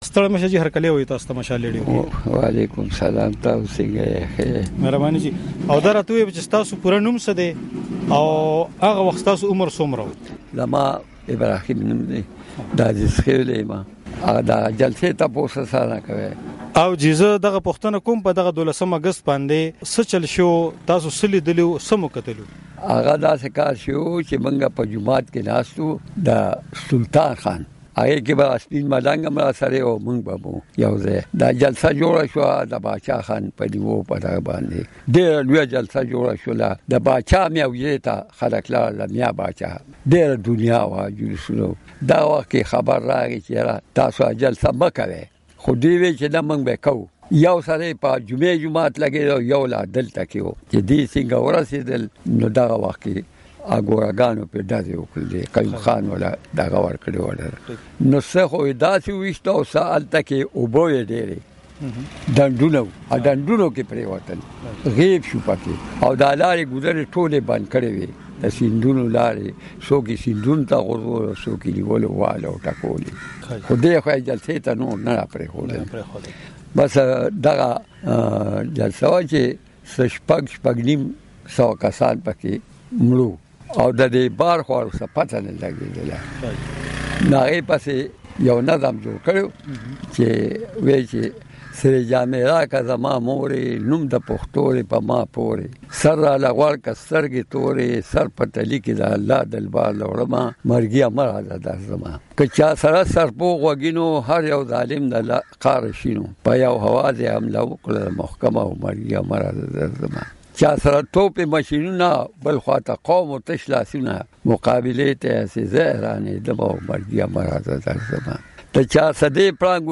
مرکه کړې